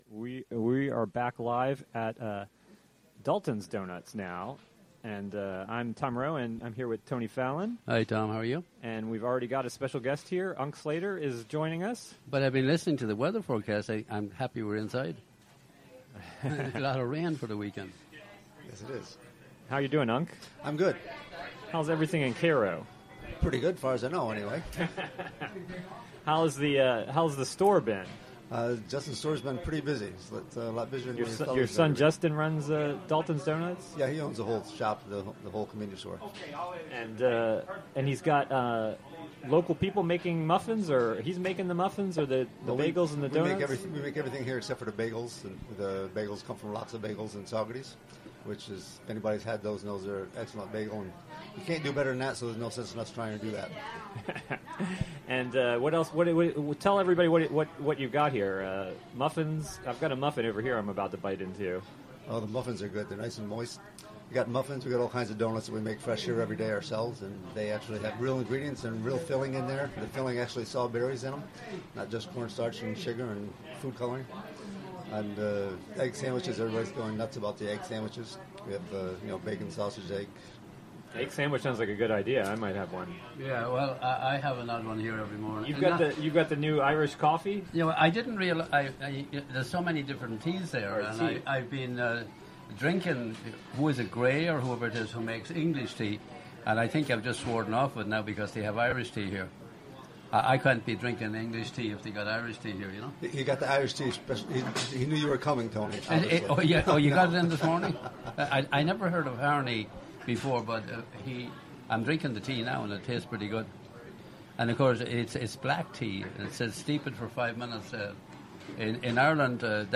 Remote Broadcast from Dalton's Donuts in Cairo: Jun 16, 2017: 9am - 11am
Interview during live broadcast.